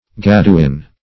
Search Result for " gaduin" : The Collaborative International Dictionary of English v.0.48: Gaduin \Gad"u*in\, n.[NL. gadus codfish.]